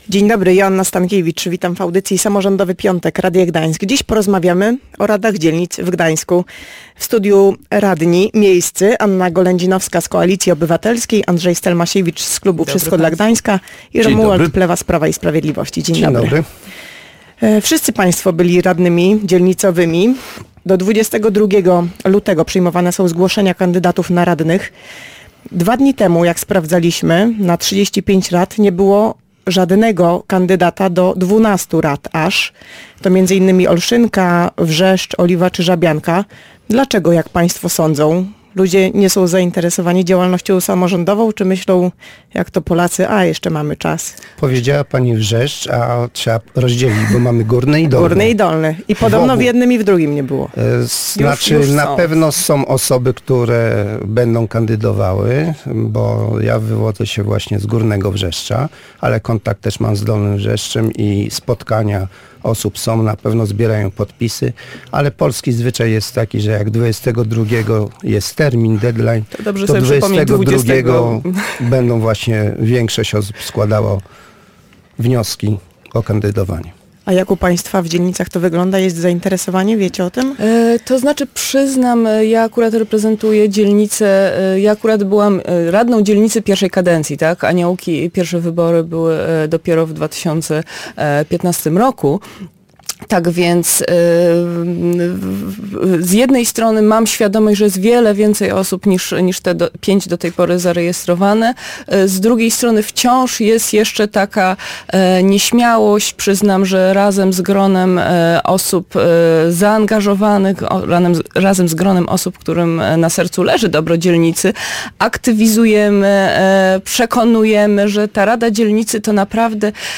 w audycji Samorządowy Piątek byli radni miasta Gdańska